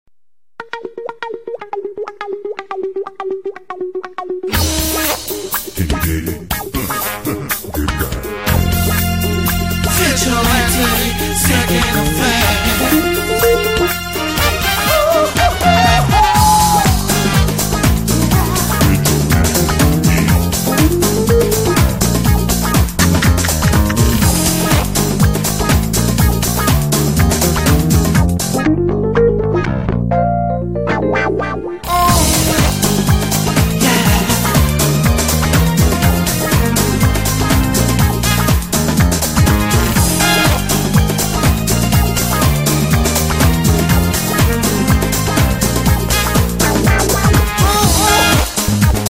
disco
из игр
funk